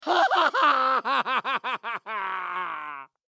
Звук насмешки
krutaya-nasmeshka.mp3